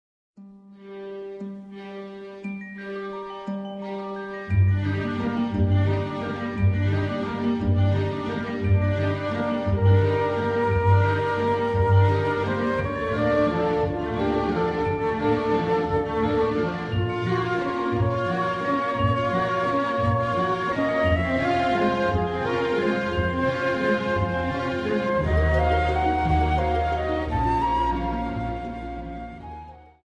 Dance: Viennese Waltz